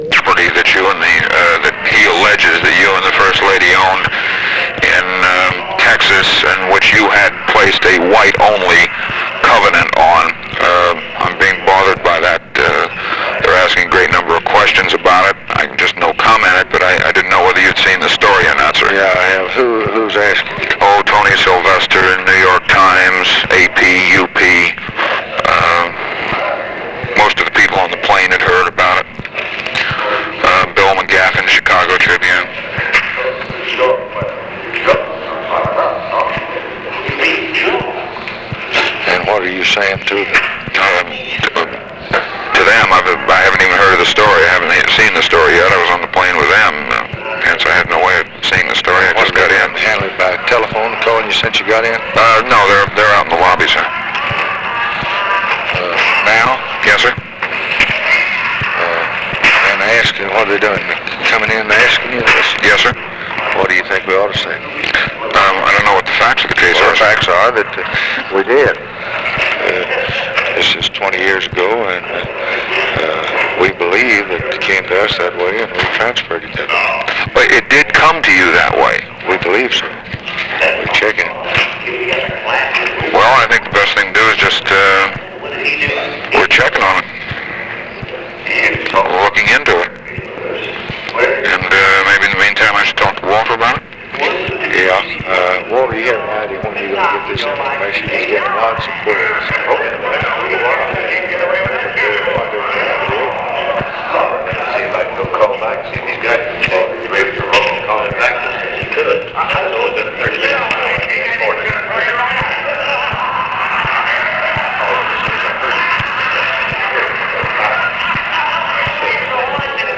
Conversation with MAC KILDUFF and OFFICE CONVERSATION, September 15, 1964
Secret White House Tapes